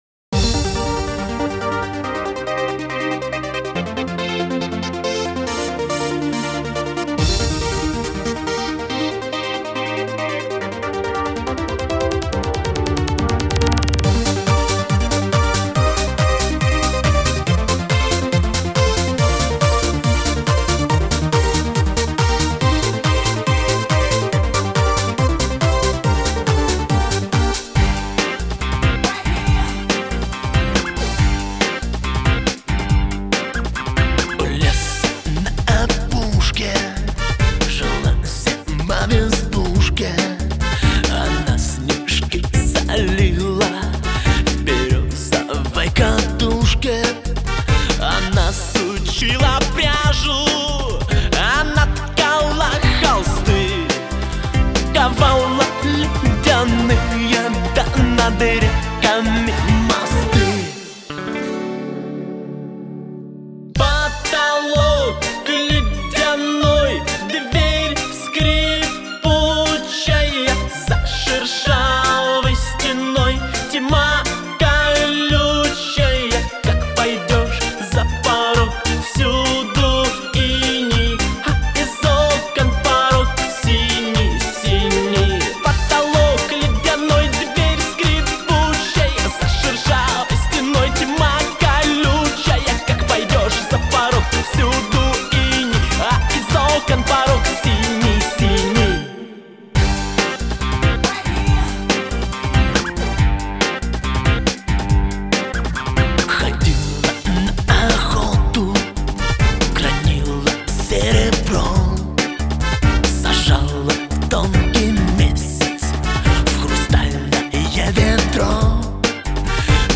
پاپ، فولک